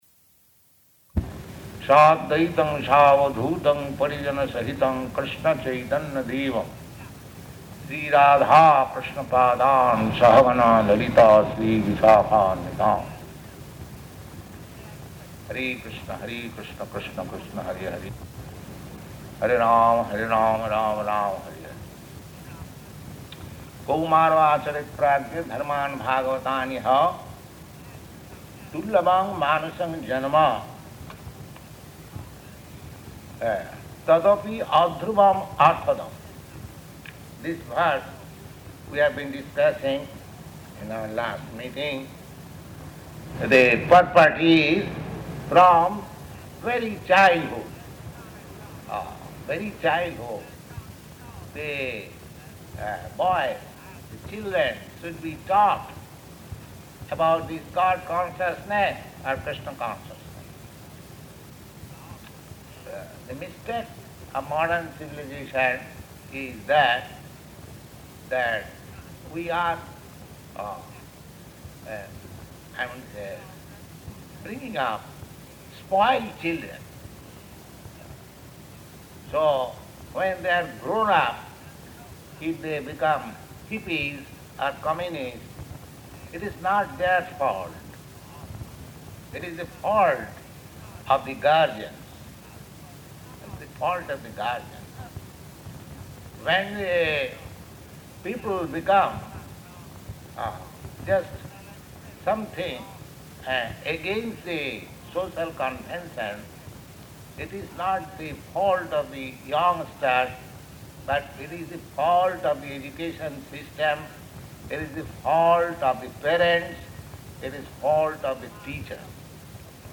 Location: Montreal